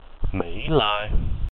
The My Lai massacre (/m l/ MEE LY; Vietnamese: Thảm sát Mỹ Lai [tʰâːm ʂǎːt mǐˀ lāːj]